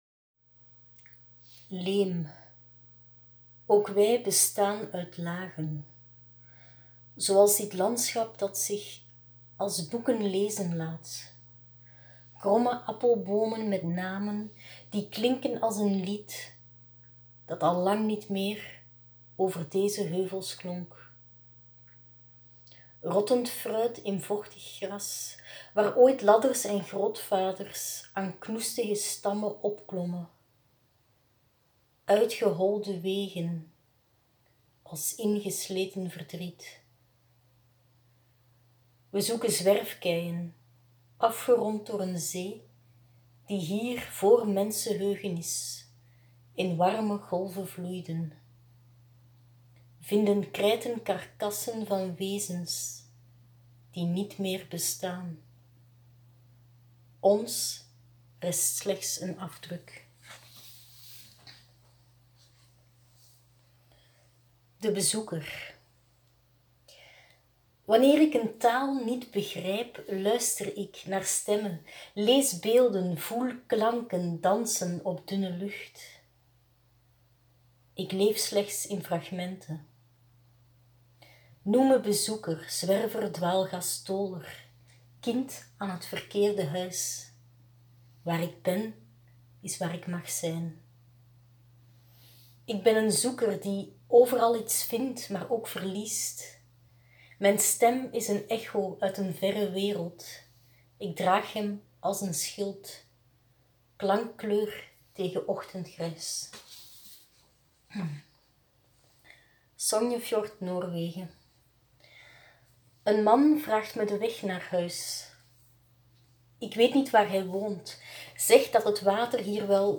Ik lees enkele gedichten voor
Daarom sprak ik 5 gedichten in.